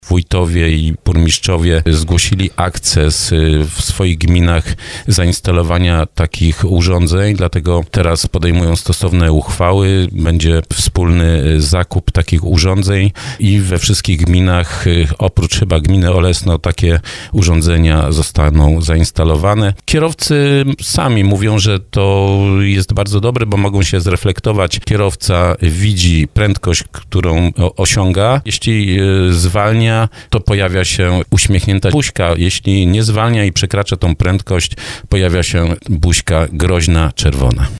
Starosta dąbrowski Lesław Wieczorek, który był gościem audycji Słowo za Słowo mówił, że radarowy pomiar prędkości się sprawdza.